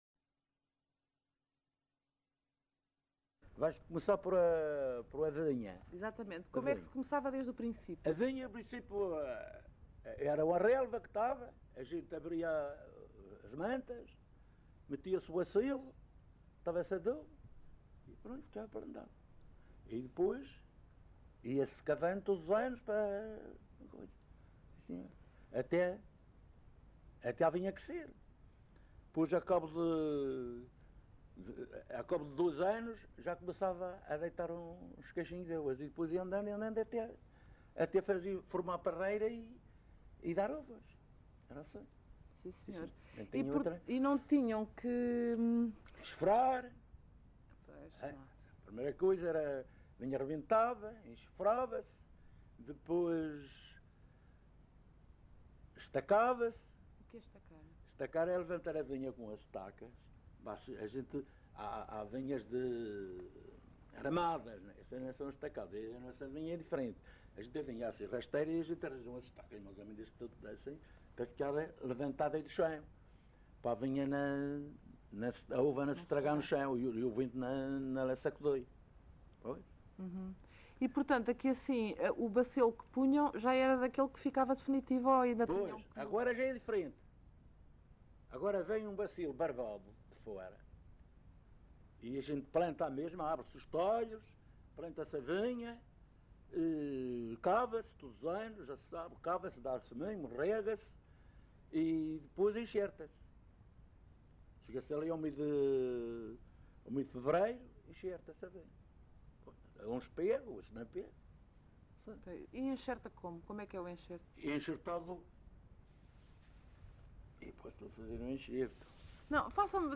LocalidadeCamacha (Porto Santo, Funchal)